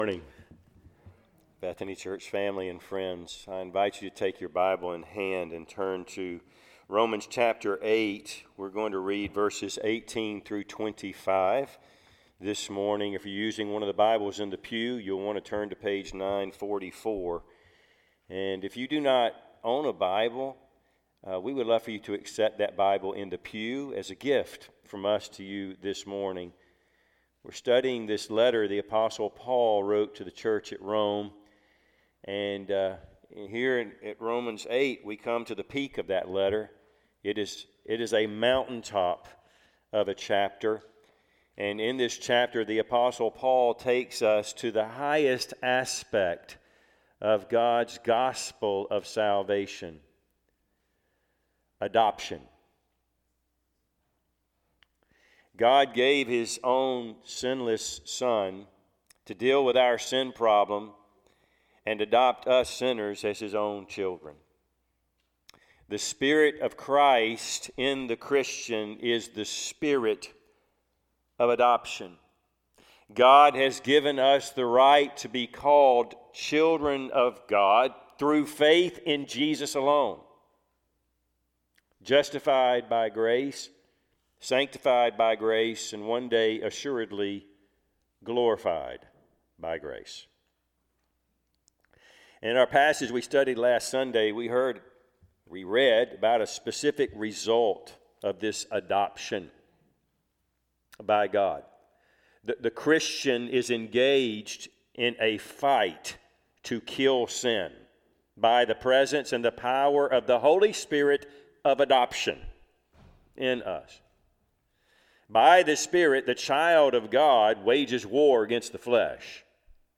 Passage: Romans 8:18-25 Service Type: Sunday AM